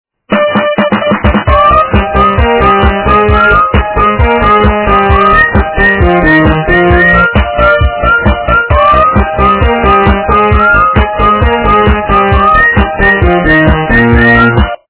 - русская эстрада
качество понижено и присутствуют гудки